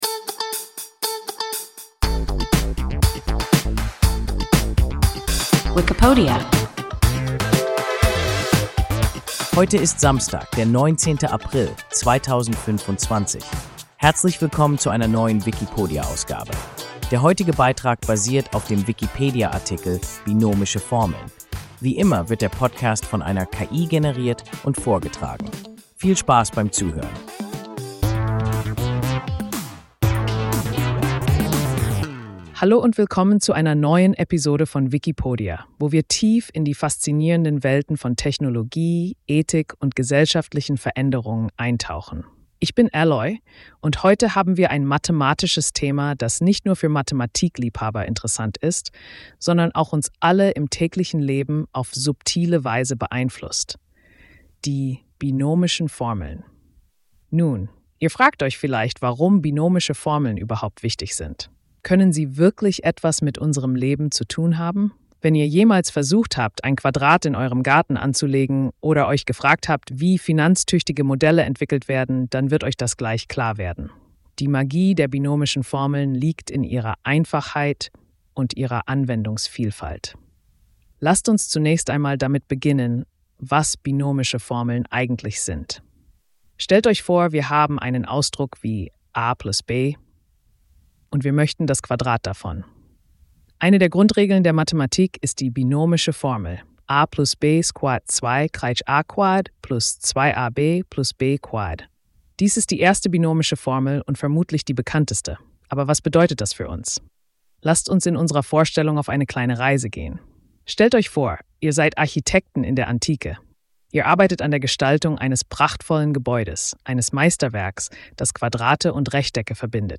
Binomische Formeln – WIKIPODIA – ein KI Podcast